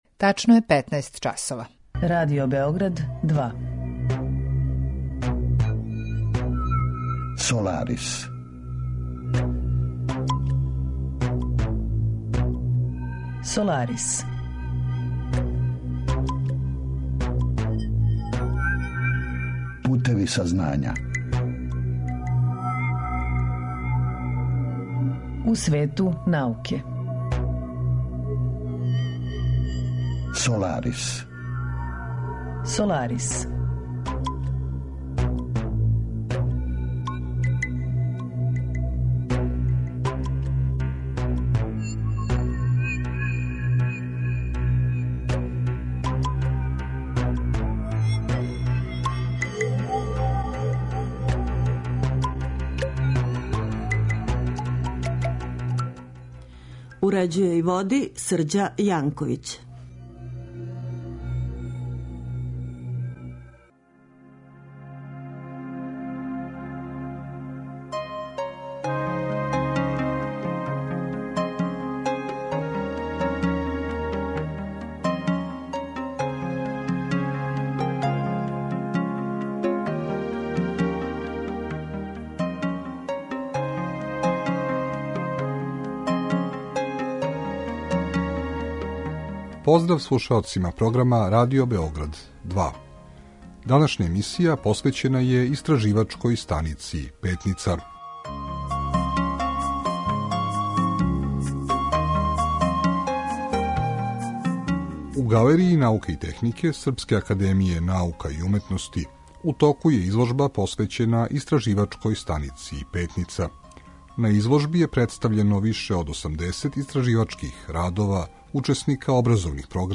Саговорник